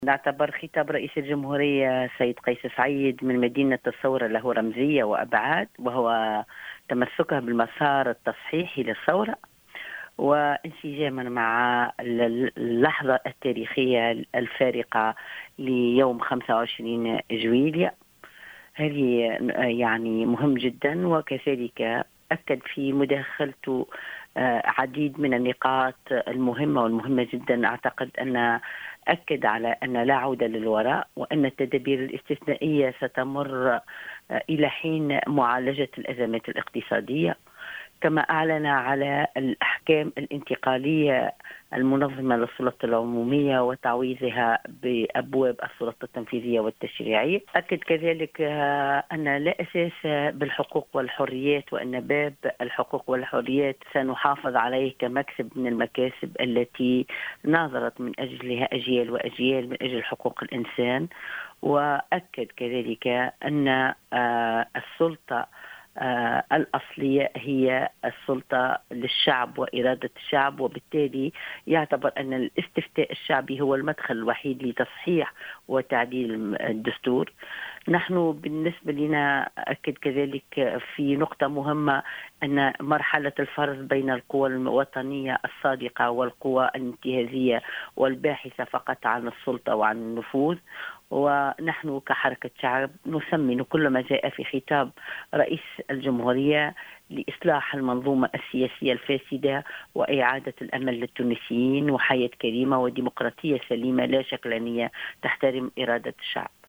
وأضافت الحداد، في تصريح للجوهرة أف أم، أنّ خطاب الرئيس تضمن نقاطا مهمة جدا، على غرار مواصلة العمل بالتدابير الاستثنائية، إلى حين معالجة الأزمات الاقتصادية، وأعلن أيضا عن وضع الأحكام الانتقالية المُنظمة للسلط العمومية، مؤكدا تمسكه باحترام ماجاء في باب الحقوق والحريات في الدستور، إضافة إلى الاستفتاء الشعبي، الذي يُعّد المدخل الوحيد لصحيح وتعديل الدستور، وذلك انطلاقا من إيمانه بأنّ السلطة الأصلية تعود للشعب ولإرادته، حسب تعبيرها.